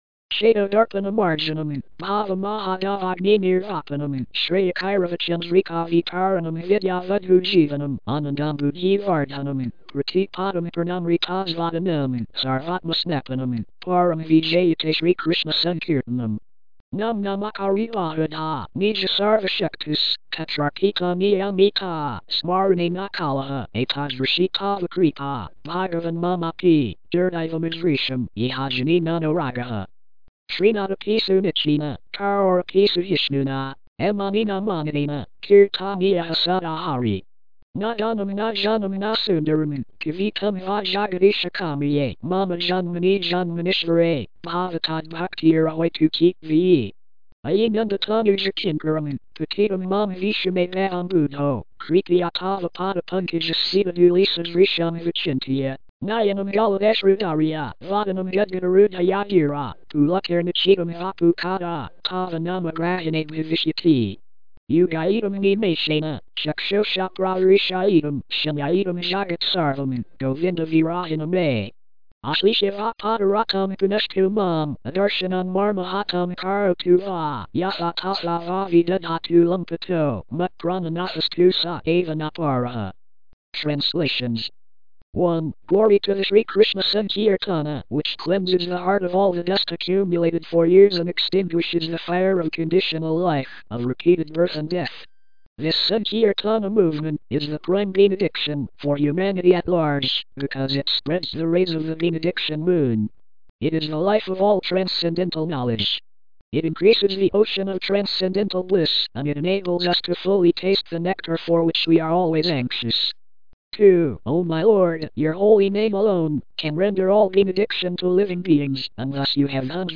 The Mahāmantra is divinely and supremely great, is online 24/7 eternally, and has been edited primarily for reading/chanting by (XPLAT) DeskBot/Sukagent & Espeak) English TTS voices.
SiksatakamTTS.mp3